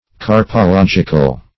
Carpological \Car`po*log"i*cal\, a.